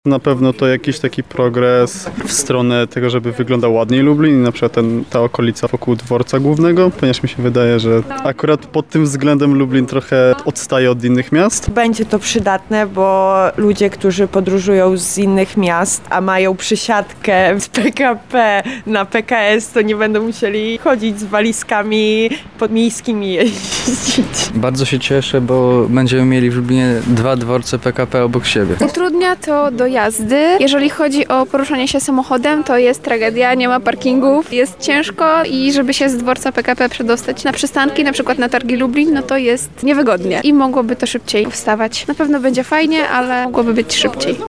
Zapytaliśmy mieszkańców Lublina, co myślą o budowie Dworca Metropolitalnego:
Sonda